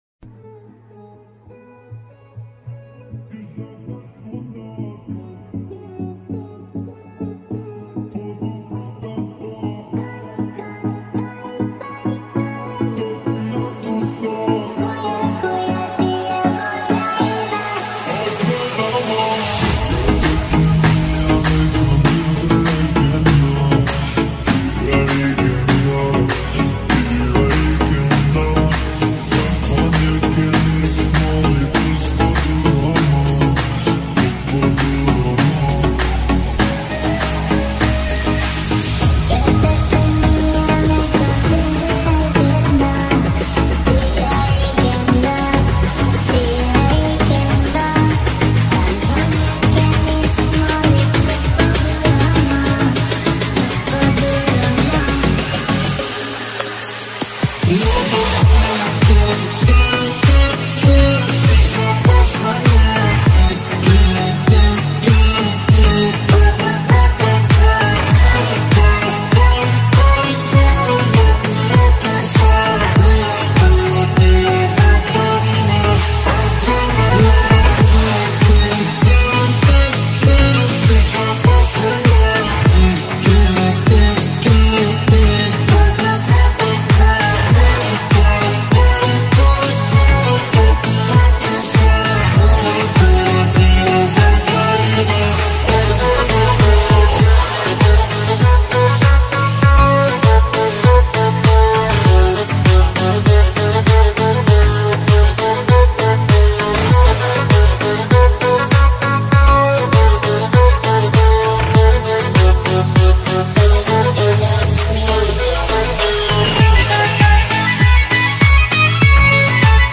ریمیکس آهنگ عربی بیس دار مخصوص سیستم صوتی ماشین